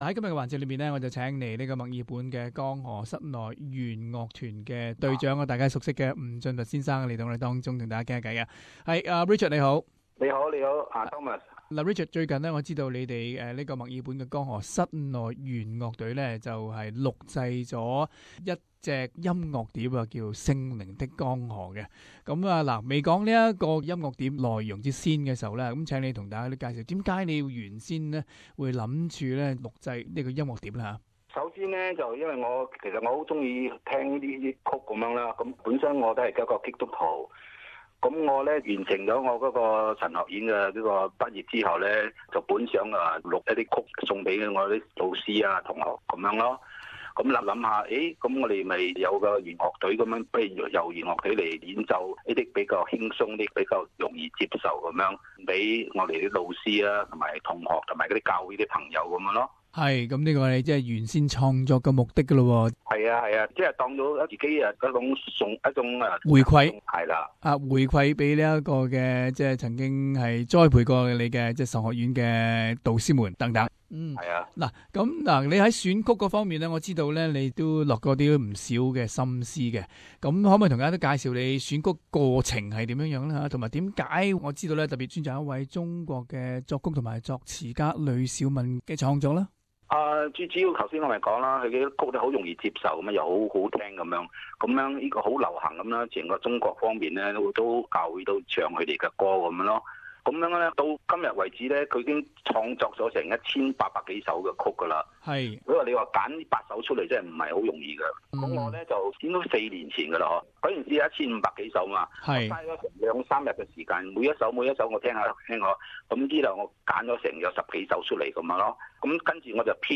【社團專訪】訪問墨爾本江河室内絃樂隊製作音樂光碟饋贈社區